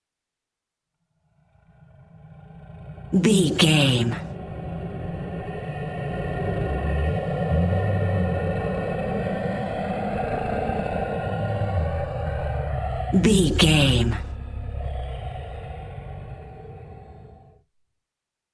Wind evil slow growl air
Sound Effects
Atonal
ominous
dark
eerie